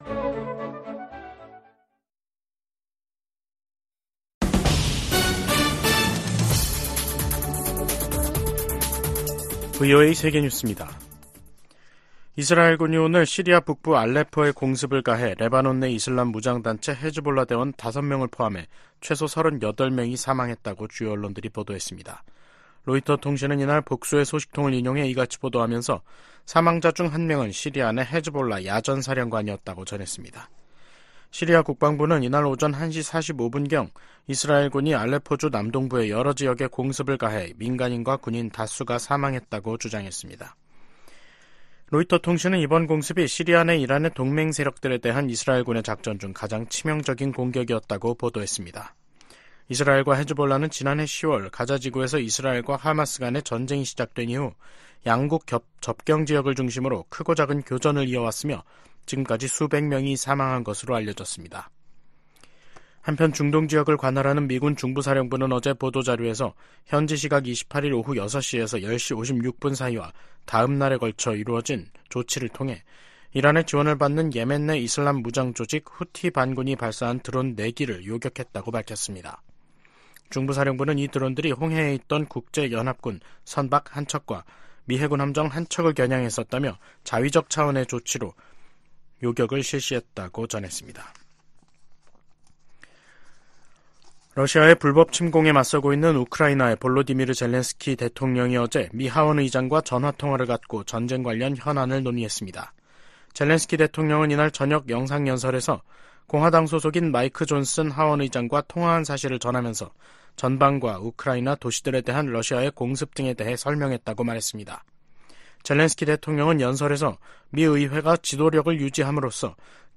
VOA 한국어 간판 뉴스 프로그램 '뉴스 투데이', 2024년 3월 29일 2부 방송입니다. 유엔 안보리 대북 결의 이행을 감시하는 전문가패널의 임기 연장을 위한 결의안 채택이 러시아의 거부권 행사로 무산됐습니다. 이에 미국과 한국·일본 등은 러시아를 강하게 비판했습니다.